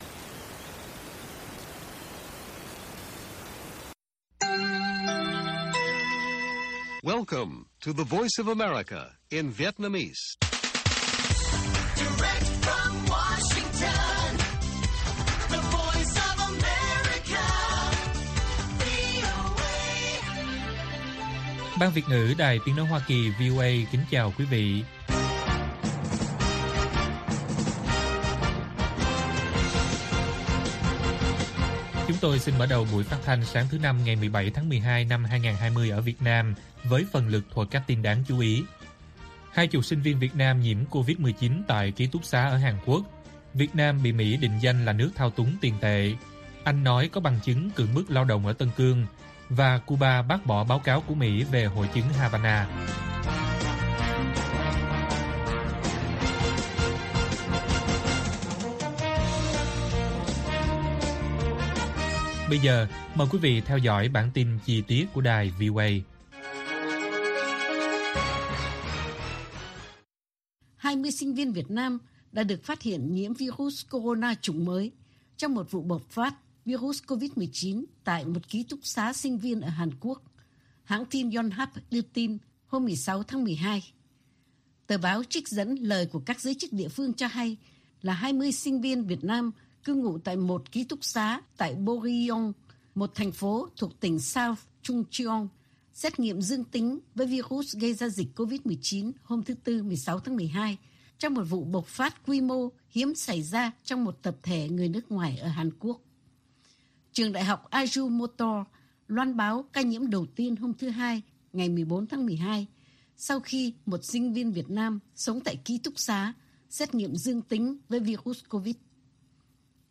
Bản tin VOA ngày 17/12/2020